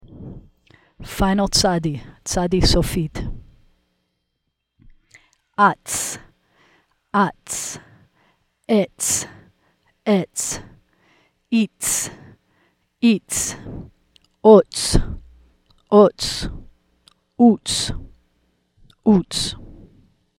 The dark green button has the sound file that goes with the reading practice from the bottom of the lesson page.
Letter Practice Sound Files